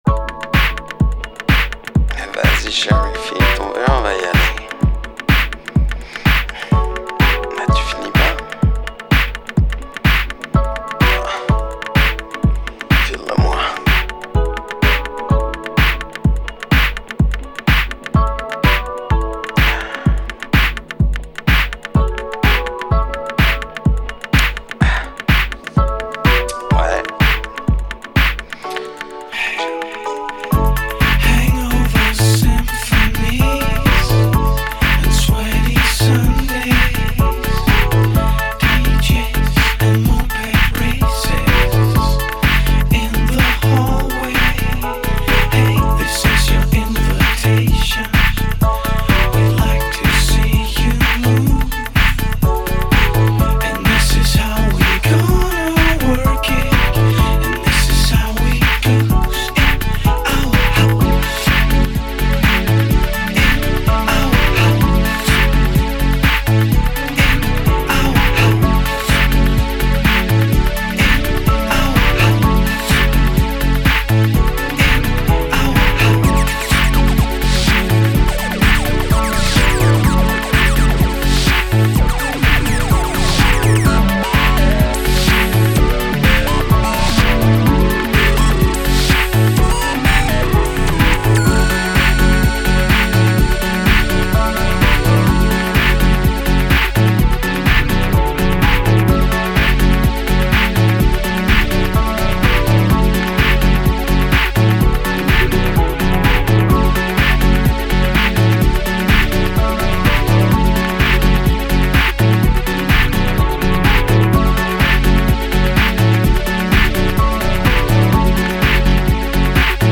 Ahahhahahha Enfin un peu de FUNK !